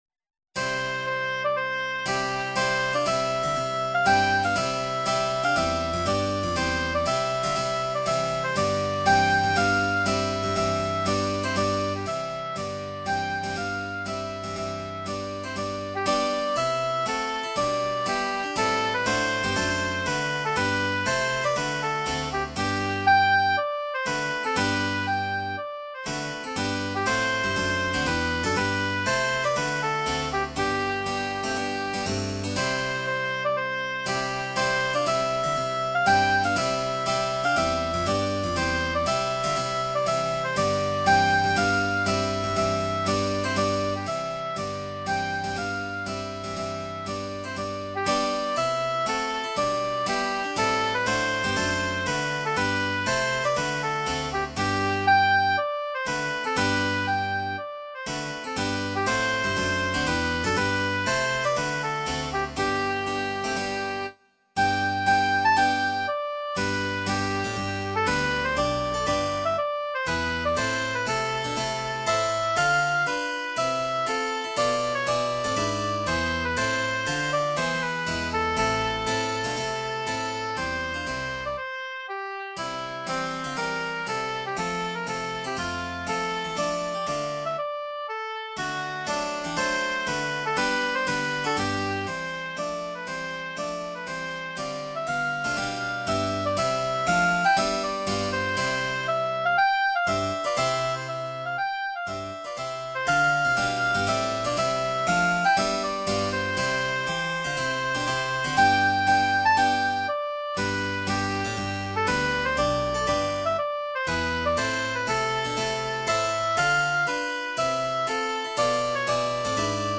Instrumentalnoten für Oboe